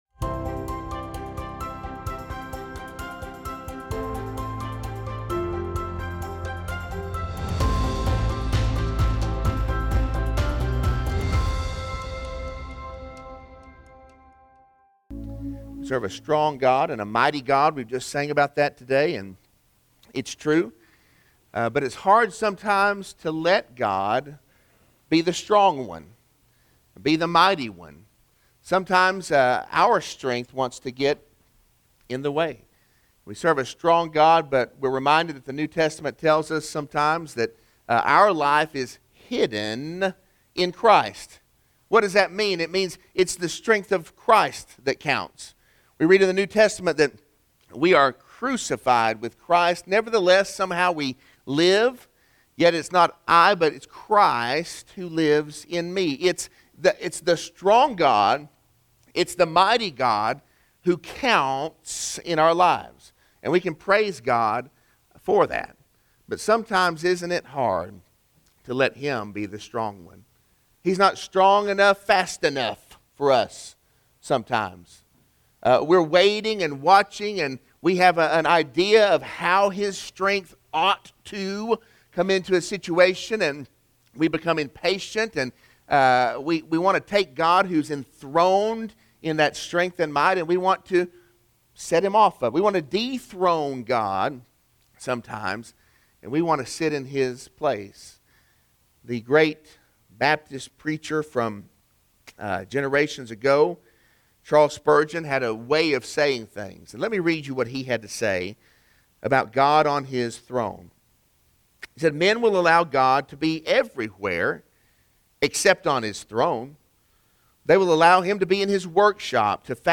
Sermon-8-29-21-audio-from-video.mp3